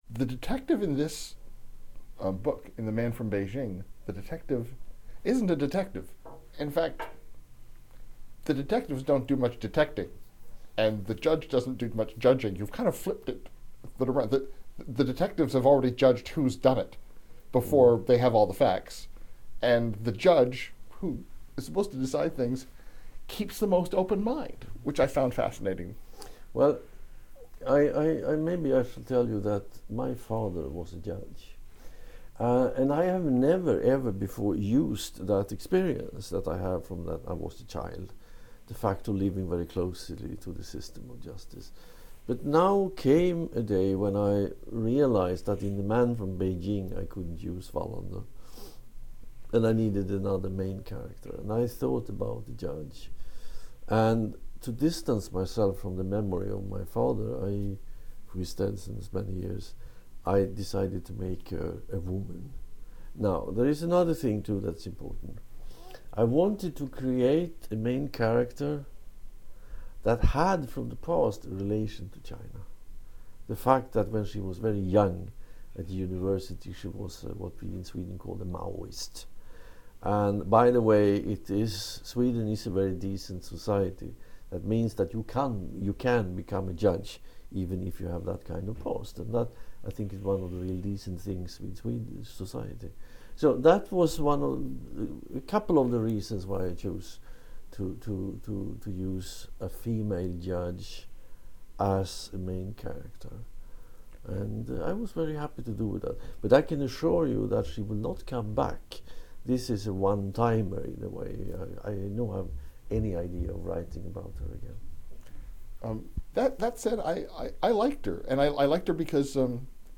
헨닝 망켈이 Bookbits 라디오에서 《베이징에서 온 남자》에 대해 이야기하고 있다.